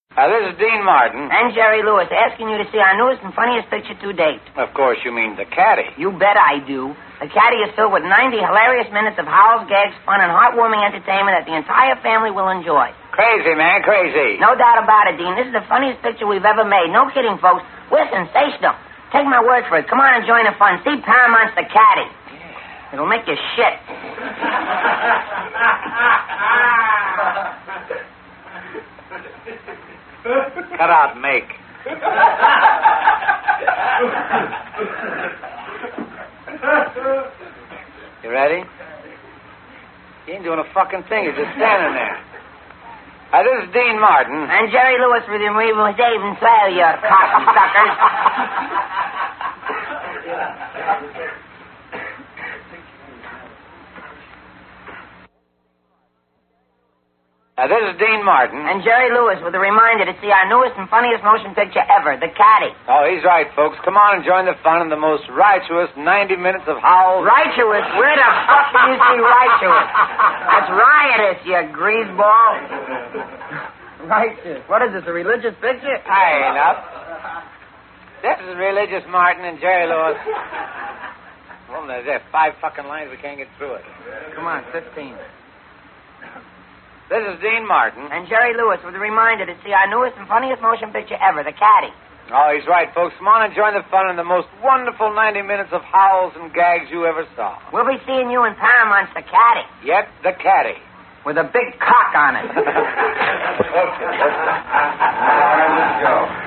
Tags: Celebrities Bloopers Barry White Celebrity bloopers Blooper Audio clips